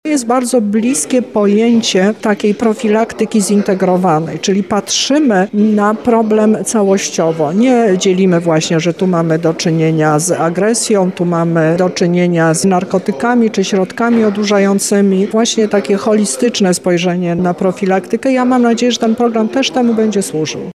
• mówi Teresa Misiuk, lubelski kurator oświaty.